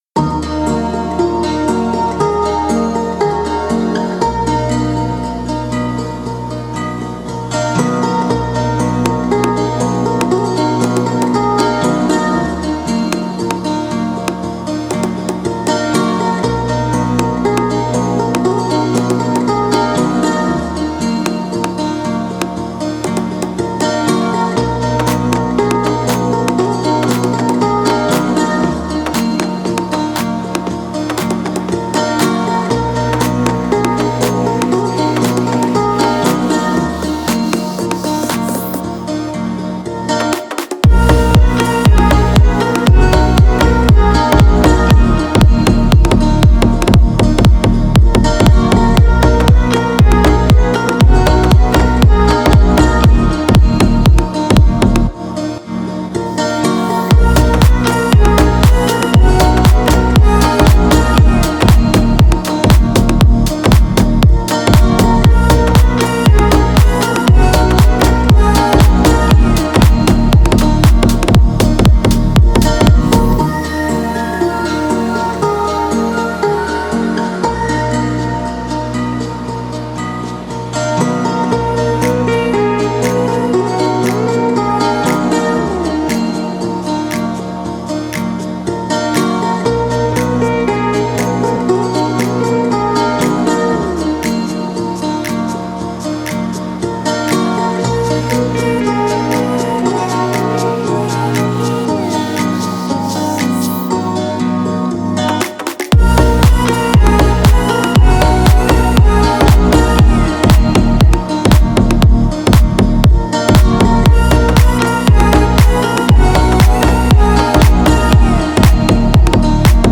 которое сочетает элементы фолка и электронной музыки.